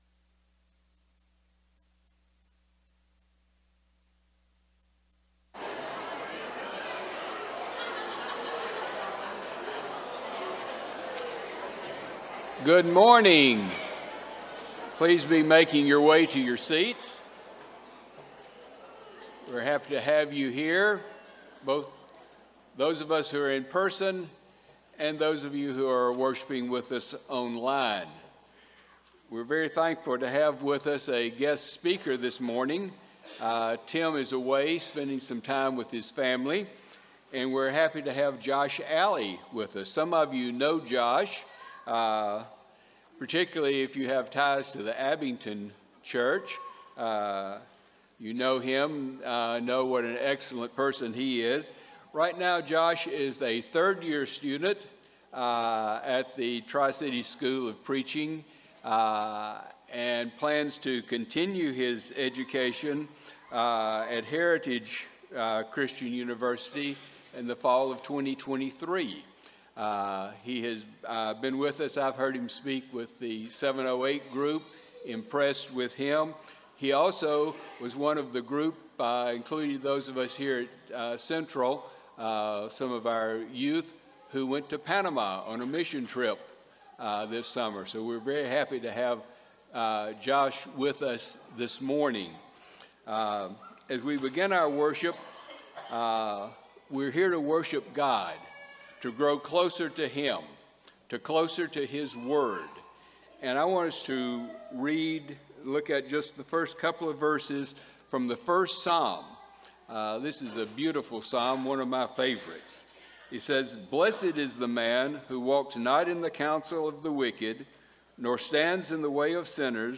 Luke 10:38 (English Standard Version) Series: Sunday AM Service